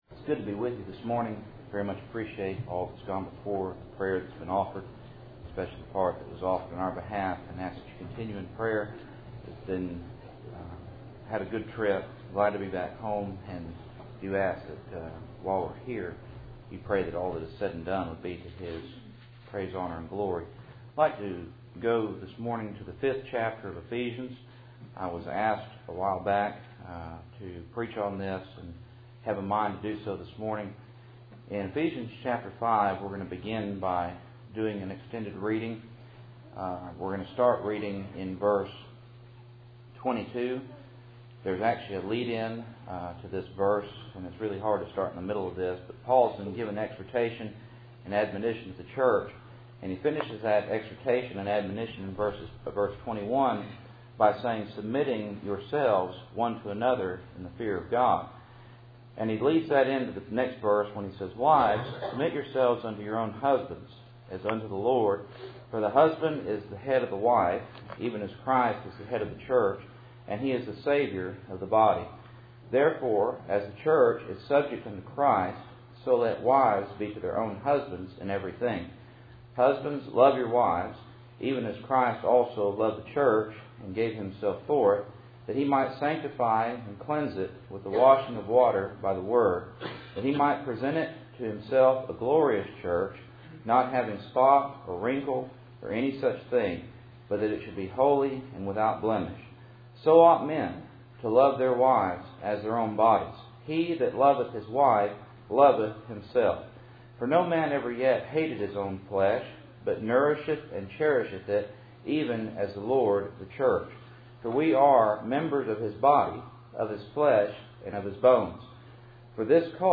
Passage: Ephesians 5:22-31 Service Type: Cool Springs PBC Sunday Morning %todo_render% « John 3:14-15 Joseph